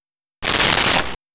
Shotgun Pump Single
Shotgun Pump Single.wav